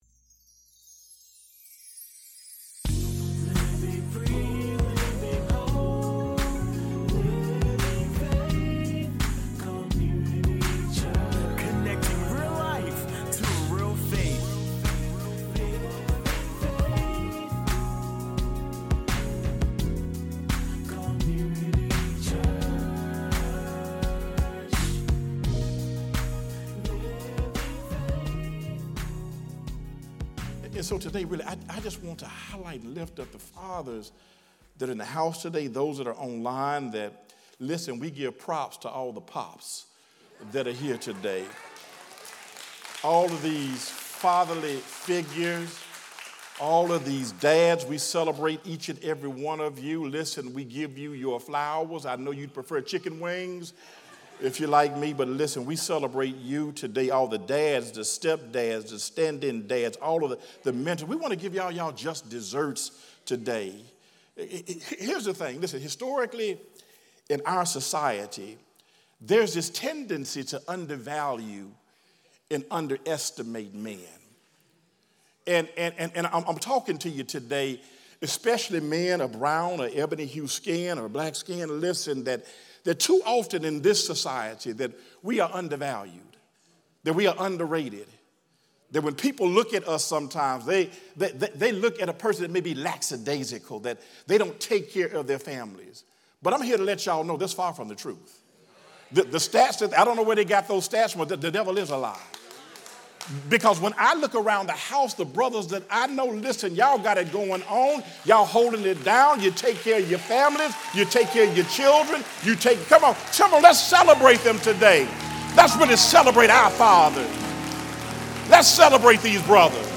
Sermons | Living Faith Community Church